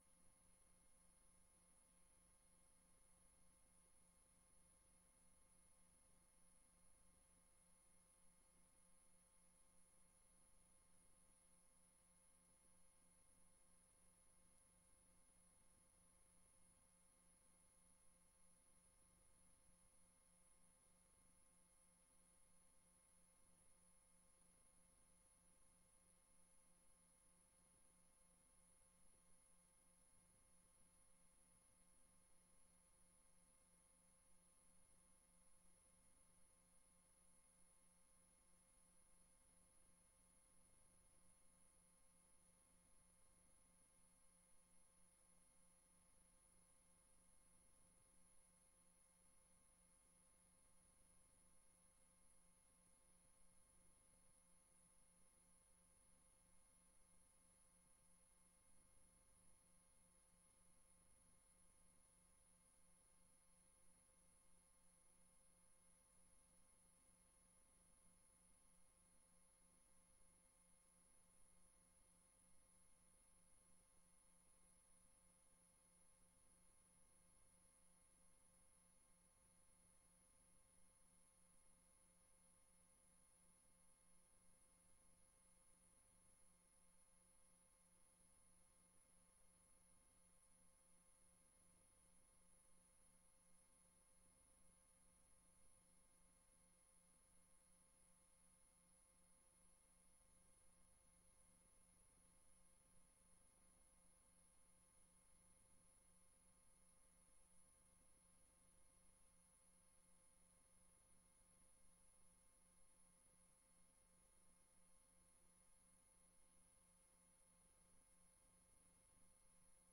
Download de volledige audio van deze vergadering
Locatie: Raadzaal